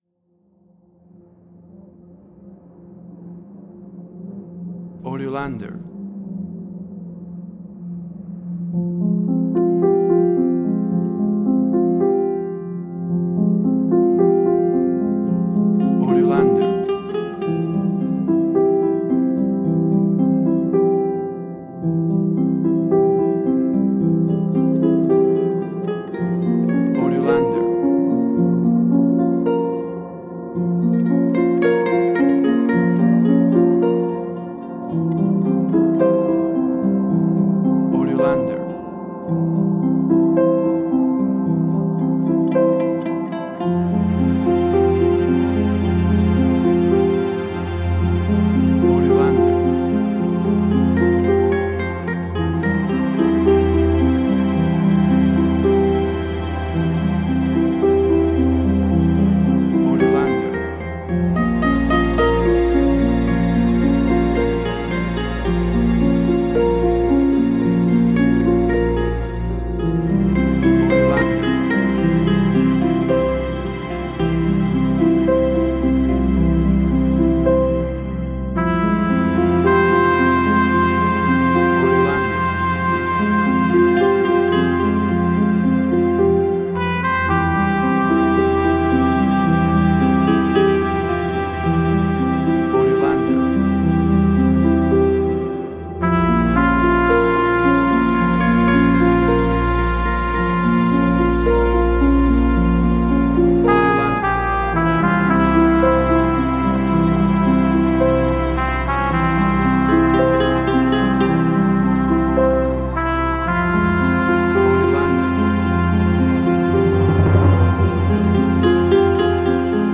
Spaghetti Western
Tempo (BPM): 55